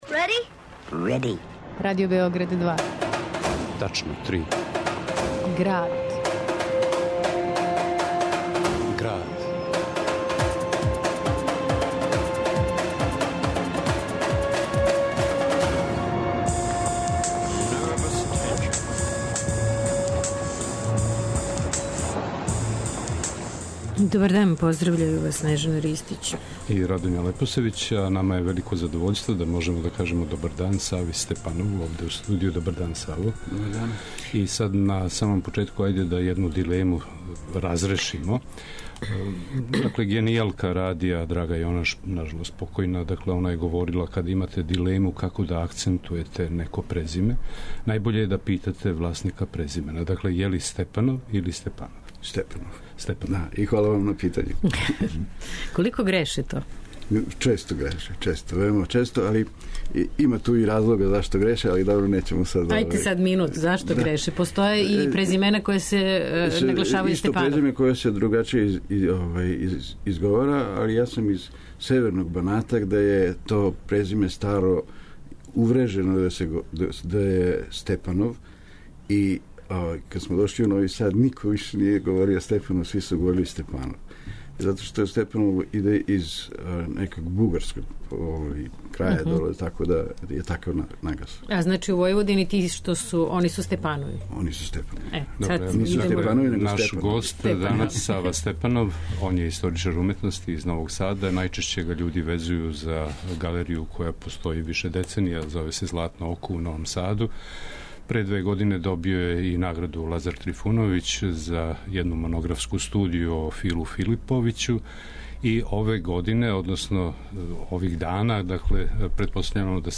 У Граду , уметници и кустоси говоре о уметности и кризи , о Дунаву као реци спајања, о новосадском Фестивалу као прилици за размену и дијалог култура 14 земаља подунавског макро региона...